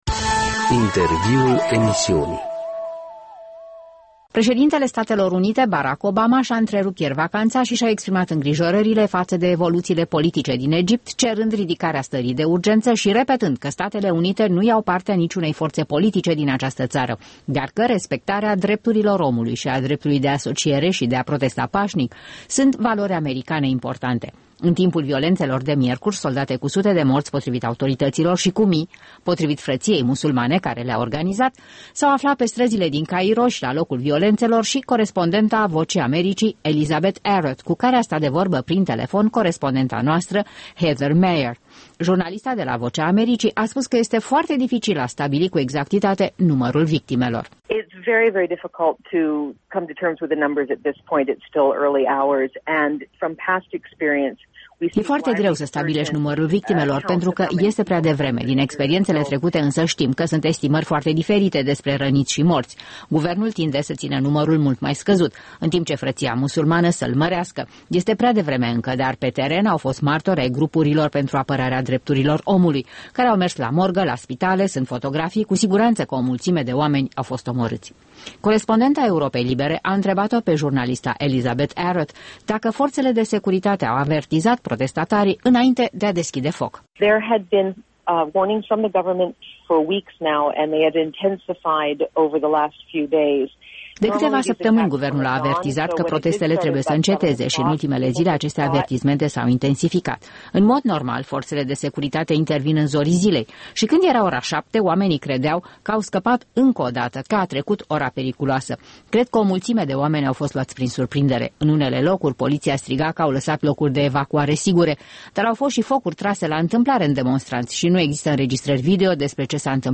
Interviuri la Europa Liberă